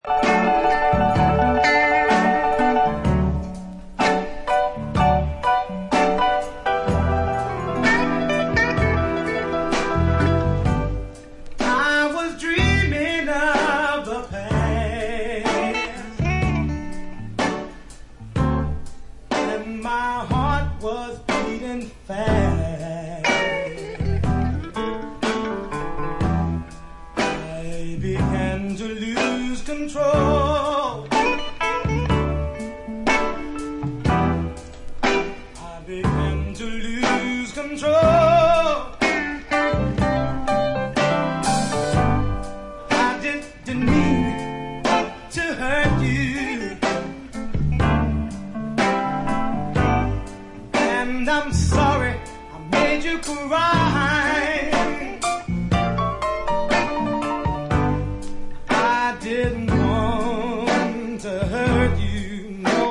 giving wonderful Jazzed-up interpretations
Soul